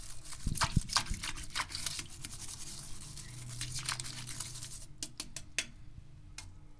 Toilet Cleaning Sound
household